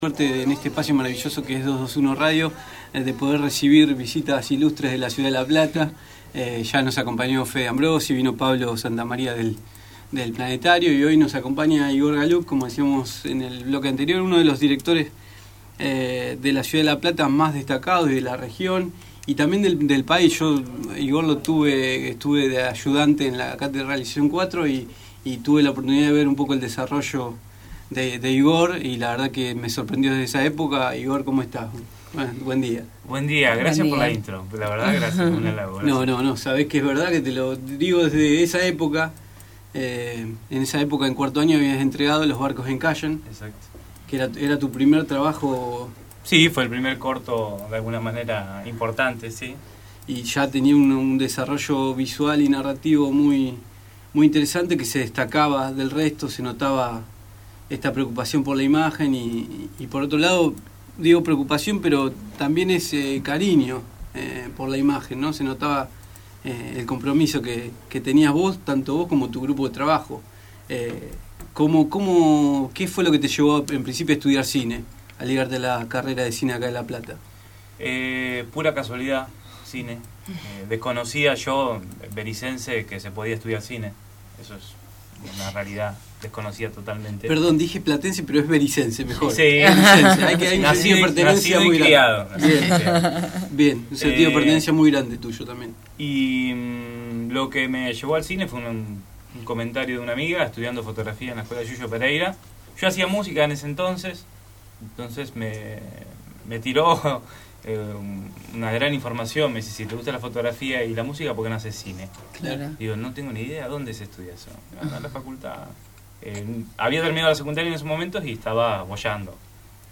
De visita en los estudios de 221Radio